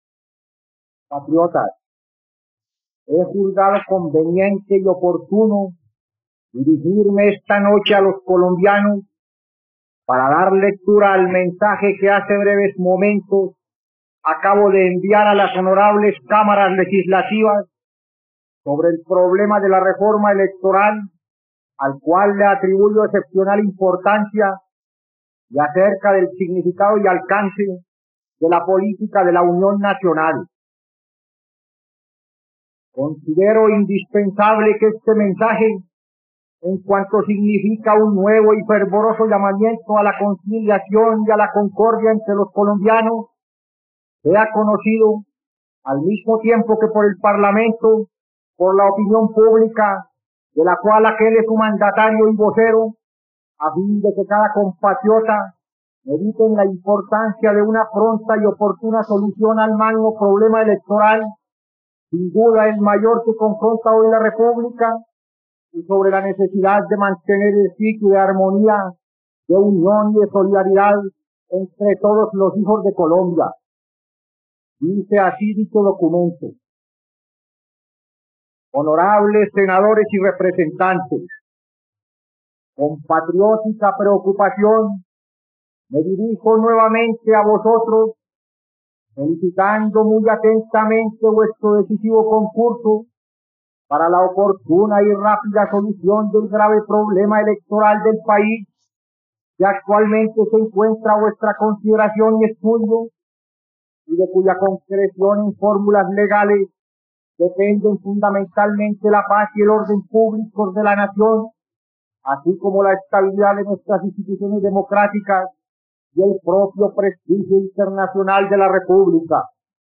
..Escucha ahora el discurso de Mariano Ospina Pérez sobre la reforma laboral, el 18 de noviembre de 1947, en la plataforma de streaming RTVCPlay.
discurso político